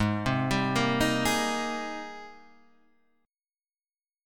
G#M9 Chord